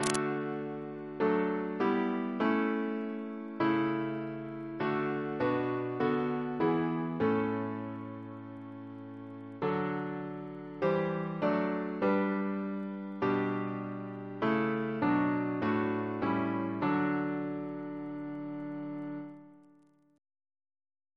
Double chant in D Composer: William Crotch (1775-1847), First Principal of the Royal Academy of Music Reference psalters: ACP: 324